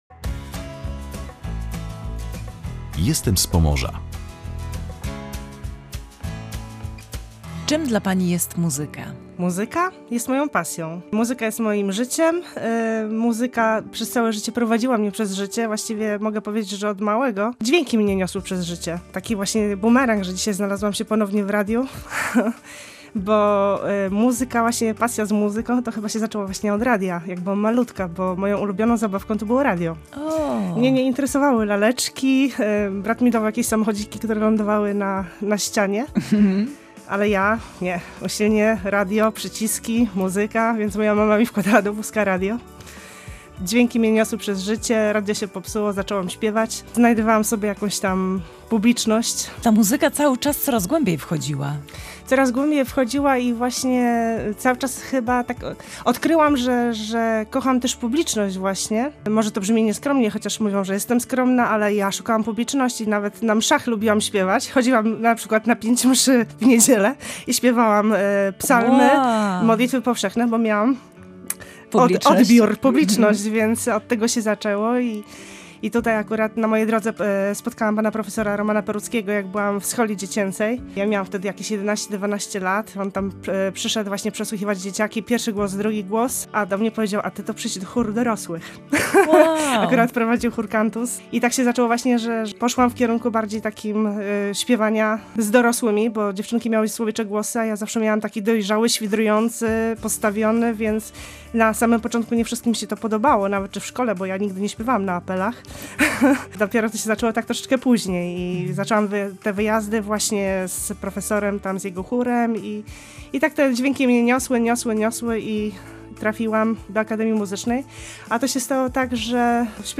Jestem z Pomorza”. Rozmawiała